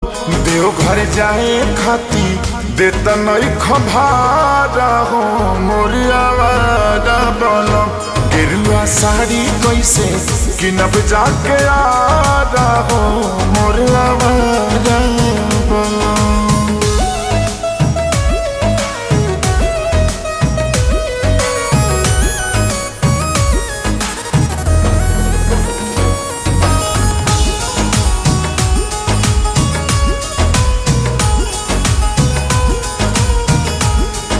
Bhojpuri Songs
Slow Reverb Version
• Simple and Lofi sound
• Crisp and clear sound